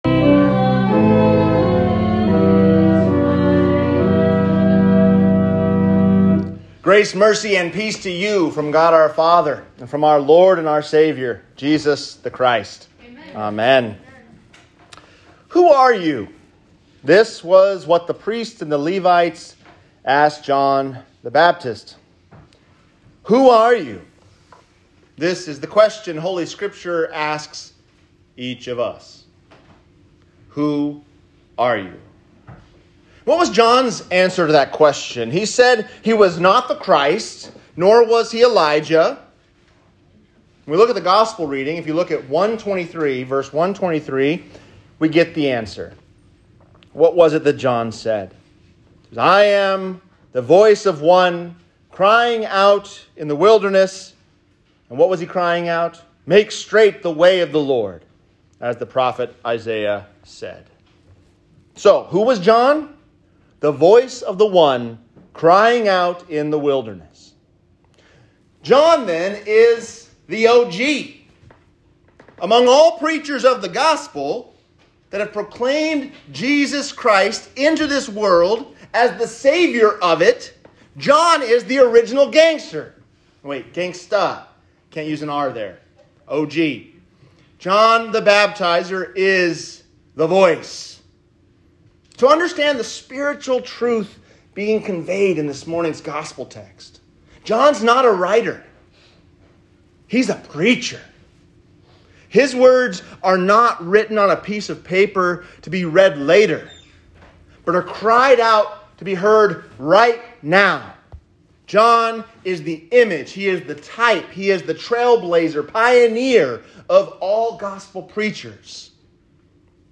Who Are You? | Sermon